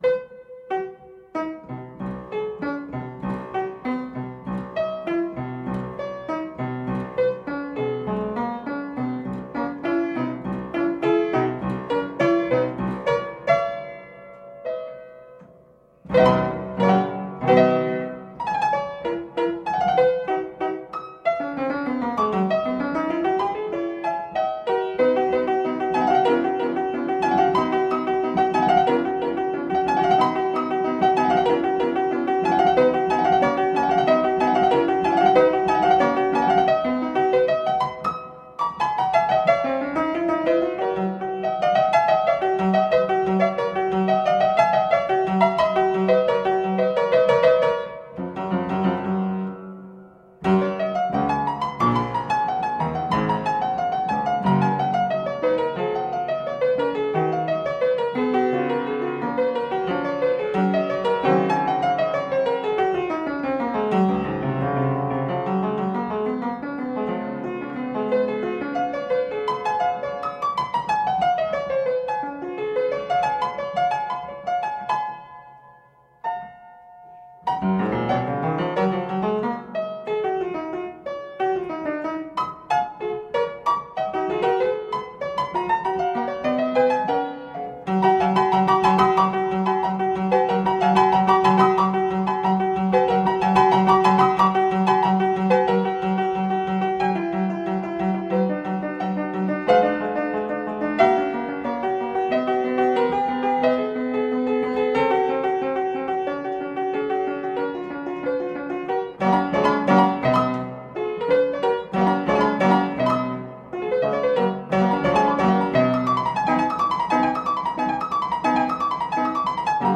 Marvelously played classical piano pieces.
Tagged as: Classical, Instrumental Classical, Piano